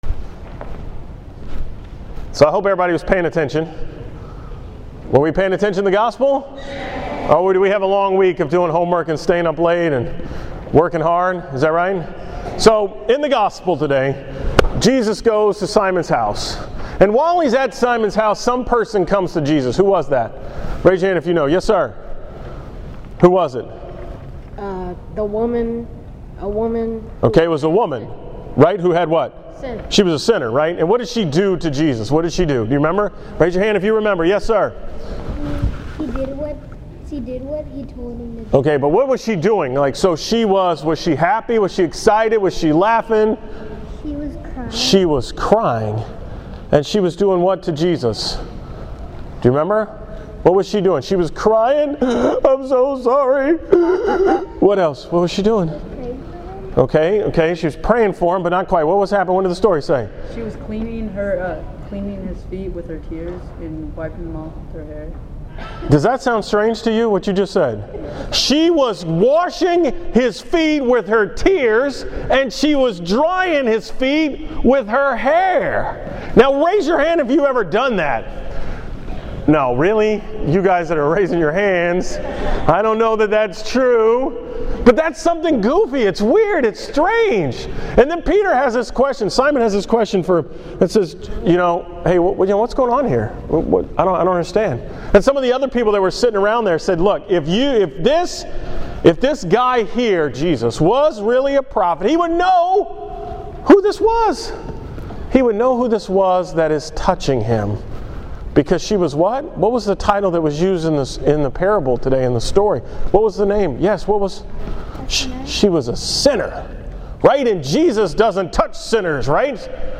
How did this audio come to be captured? From the school Mass on Thursday, September 19.